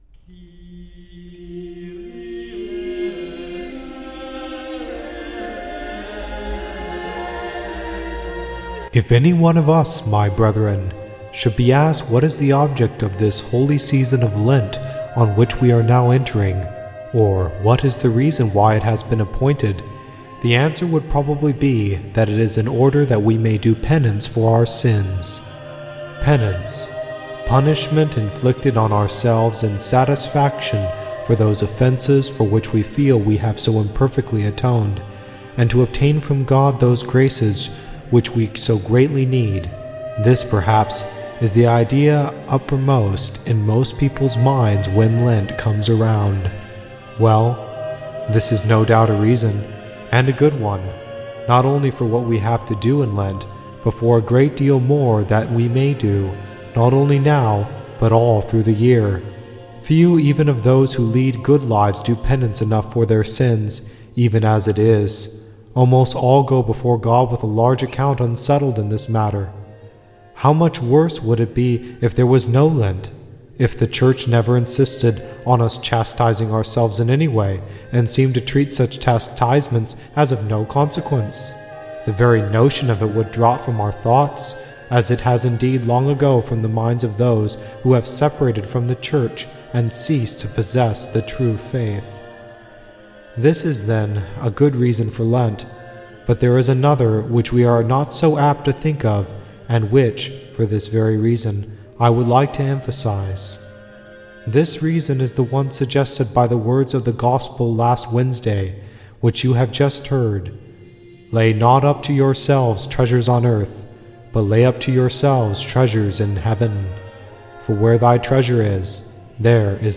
Catholic Lent Homily Series: The Merit of Fasting and Abstinence
Music used under attribution license